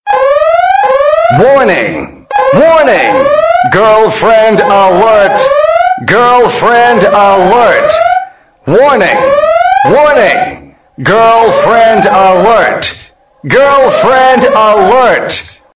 GF-Warning
GF-Warning.mp3